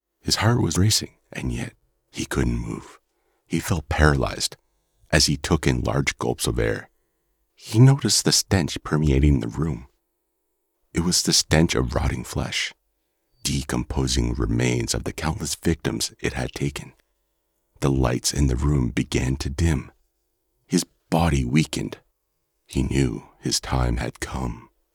My voice is characterful and clear, naturally British, mid deep with a soft, native Yorkshire/northern accent. I can perform a wide range of UK regional, Irish, Scottish and US accents.
0819The_Stench__audio_drama_.mp3